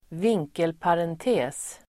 Ladda ner uttalet
Uttal: [²v'ing:kelparente:s]